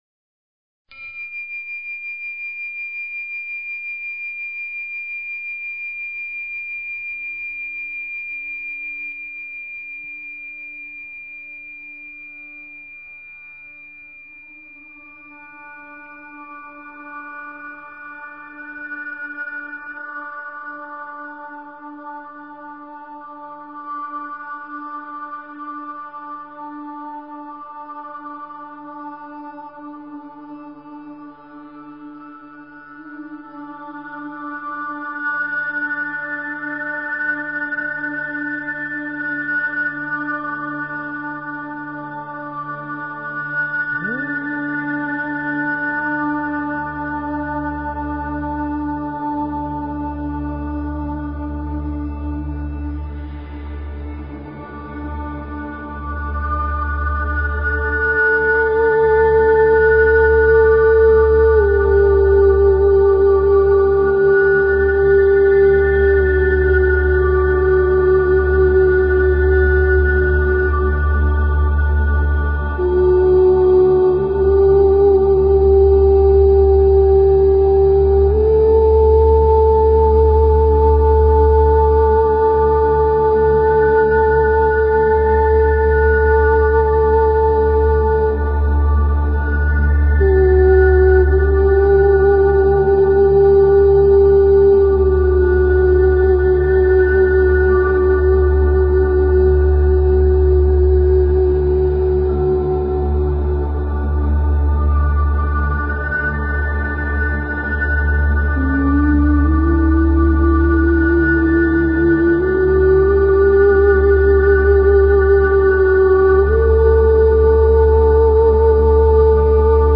Talk Show Episode, Audio Podcast, Radiance_by_Design and Courtesy of BBS Radio on , show guests , about , categorized as
This is a call in show so call in!